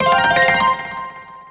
se_pause.wav